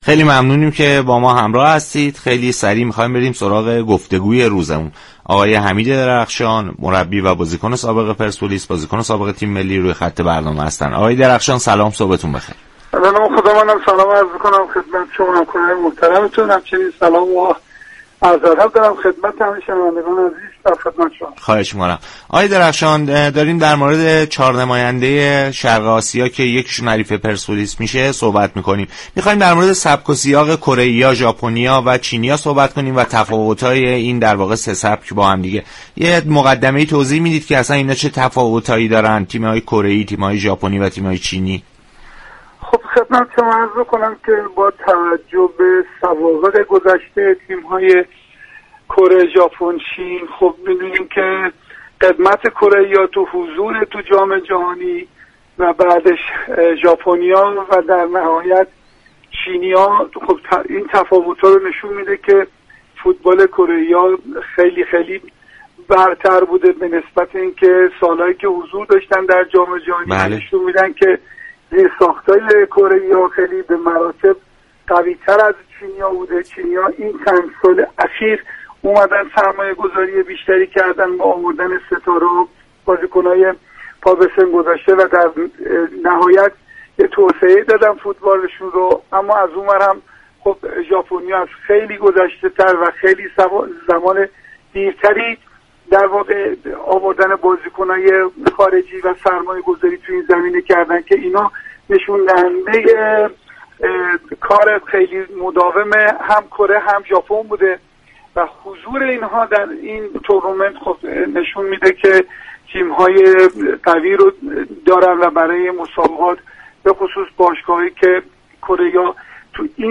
حمید درخشان در برنامه صبح و ورزش چهارشنبه 19 آذر به گفتگو درخصوص 4 نماینده شرق آسیا كه یكی از آنها حریف پرسپولیس خواهد شد؛ پرداخت.